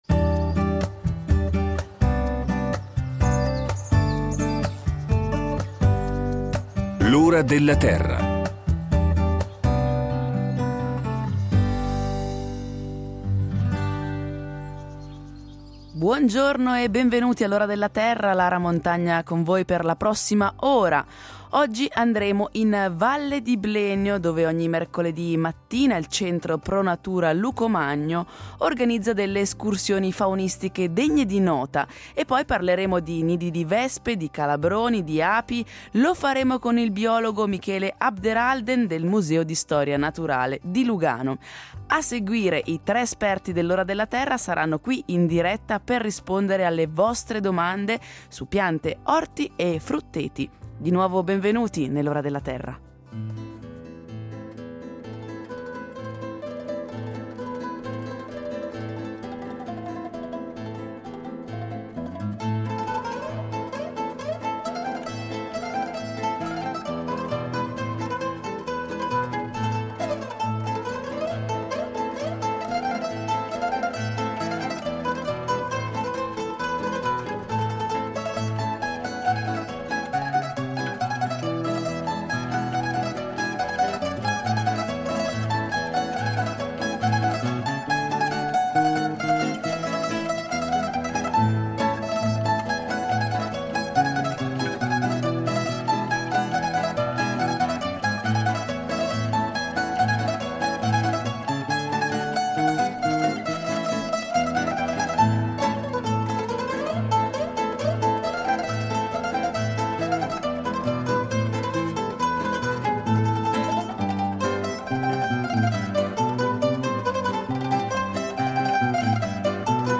I tre esperti de L’Ora della Terra torneranno al completo e in diretta, per rispondere alle vostre domande su giardini, alberi da frutta e orti.